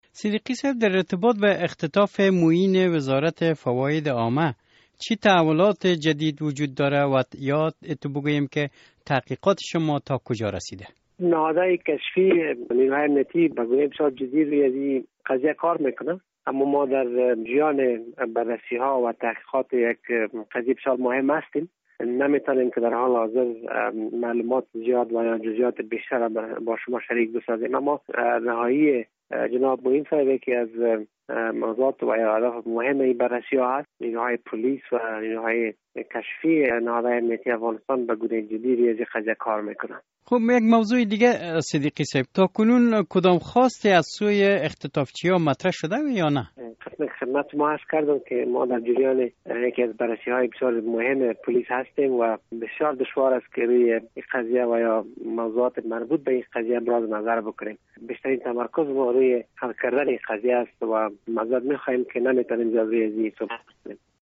مصاحبه: آخرین تحولات در مورد اختطاف معین وزارت فواید عامه